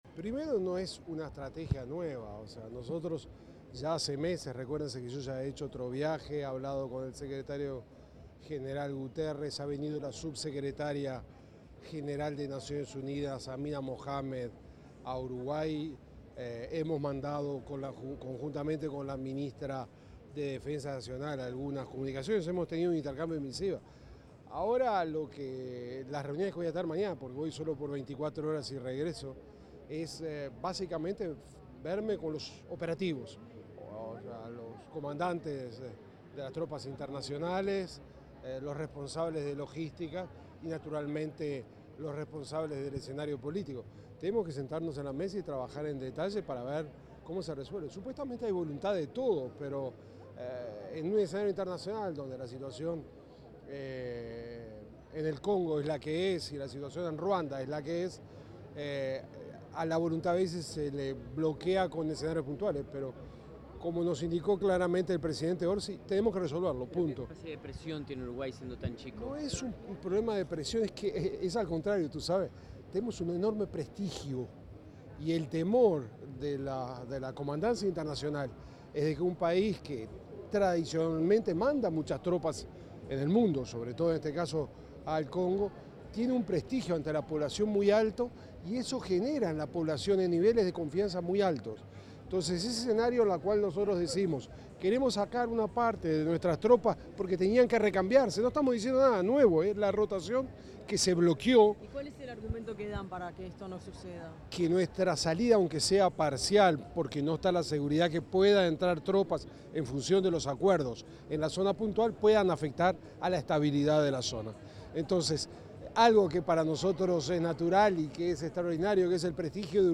Declaraciones del canciller de la República, Mario Lubetkin
Declaraciones del canciller de la República, Mario Lubetkin 17/06/2025 Compartir Facebook X Copiar enlace WhatsApp LinkedIn El ministro de Relaciones Exteriores, Mario Lubetkin, realizó declaraciones tras participar en la presentación del centro educativo Tumo en el aeropuerto internacional de Carrasco.